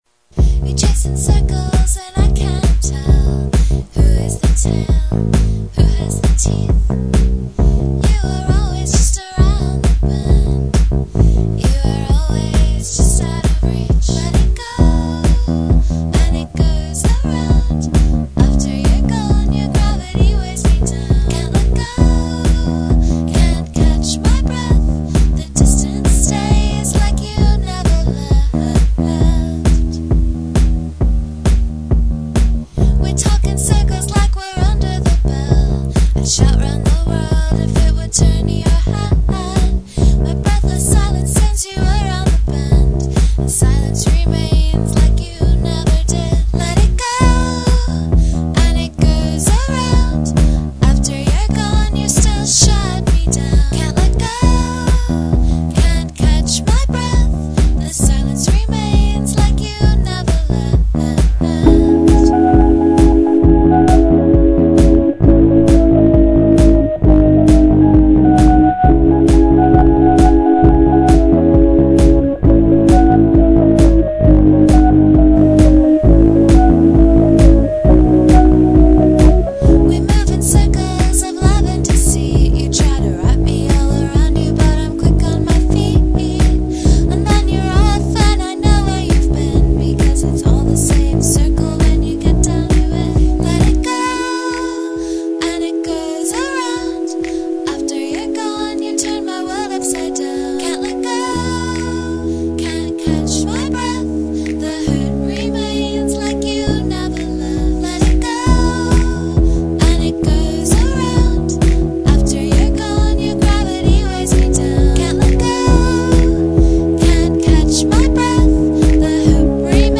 a-b-a-b-c-a-b-b-b-b
c#m (i think?)
this song is recorded on my trusty dell inspiron 6000 using vegas. i used the demo version of fruityloops to make the drum tracks (there are two). you can't save files in the demo version so i had to play them off fruityloops while recording them in sound forge, which only works for a little while before the computer totally flips out and hoses the recording. so they are pieced together, a little off rhythm in places. i recorded the vocals with a crappy mic into my behringer mixer. the vocals and drums were first, so it's a fluke that it's in c#m, and i'm going to redo them since they are a little off the instruments that were added later. the keyboard is my yamaha console organ, recorded while listening to the drum track in headphones with my camcorder recording in front of the big ol organ speaker. i'm really happy with how hissy this sounds. the bass was recorded last. it was extremely fun to jam along with myself while writing the bass part. i miss being in a band. sigh.
pop structure = gold. give me that refrain fifteen seconds in. hell, yeah. the transition from the refrain back to the verse is a little non-existent, but it totally works. organ solo bridge = yes. also very martin gore. the repeated figure over the last four bars is inspired genius. i'm not entirely sold on the refrain/refrain with more bass notes/let it gooooooo/can't let gooooo/refrain breakdown ending. i think if the arrangment changes were a bit more drastic, you could pull it off. the refrain is short, and the song is short, so it isn't a time issue, it just needs to change sonically if you're going to repeat it so many times in a row.